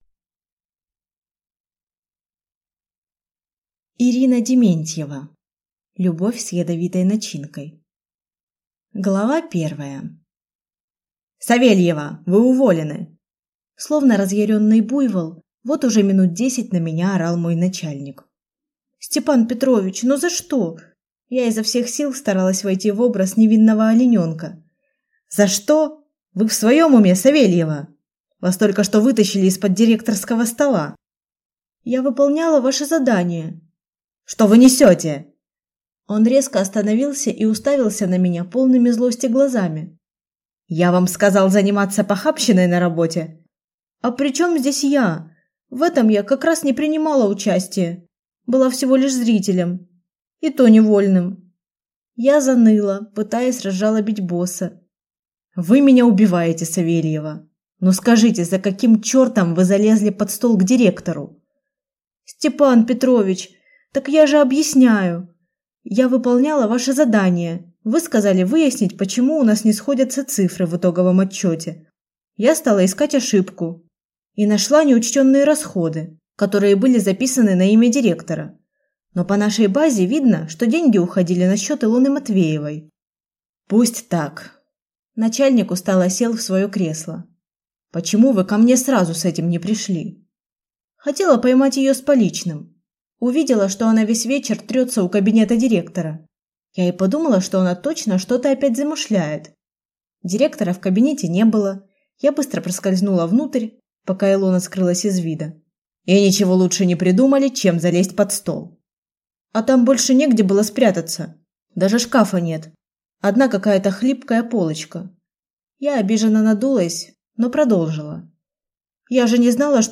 Аудиокнига Любовь с ядовитой начинкой | Библиотека аудиокниг